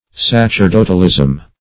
Sacerdotalism \Sac`er*do"tal*ism\, n.